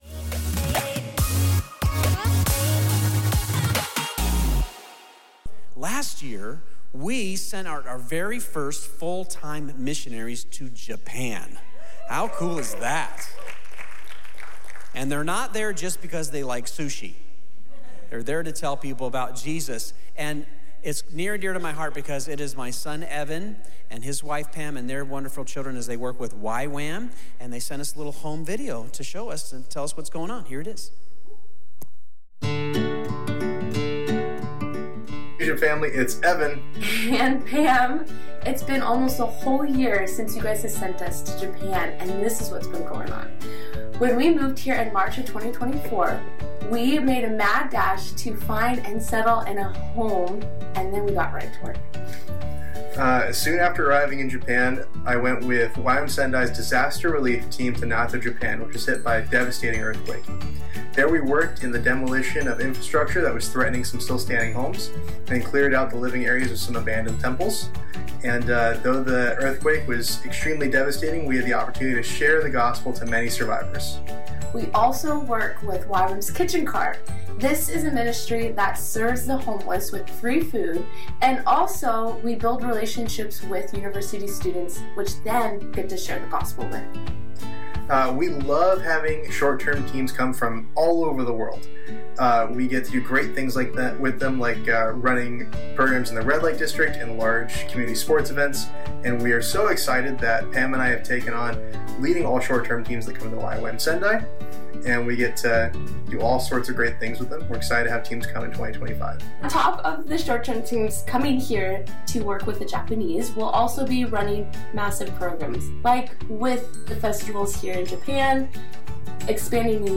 Dream-Big-Mission-Sunday.mp3